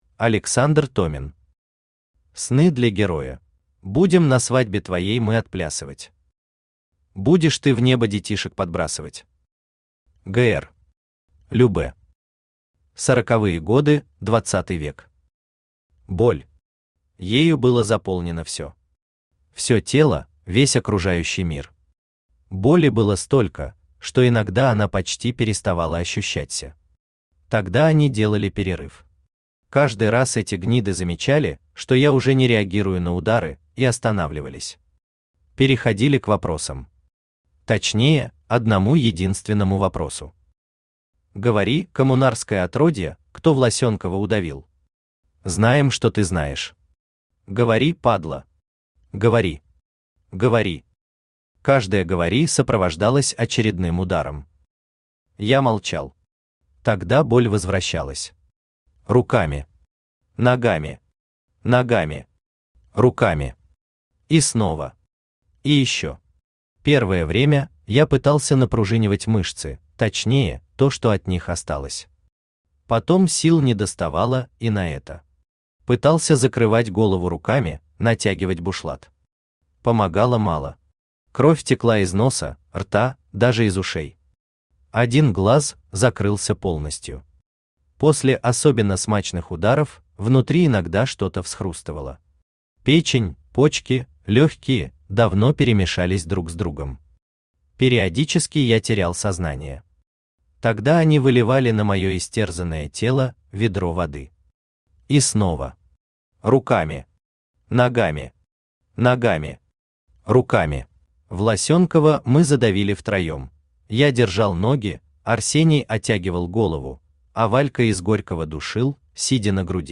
Аудиокнига Сны для героя | Библиотека аудиокниг
Aудиокнига Сны для героя Автор Александр Томин Читает аудиокнигу Авточтец ЛитРес.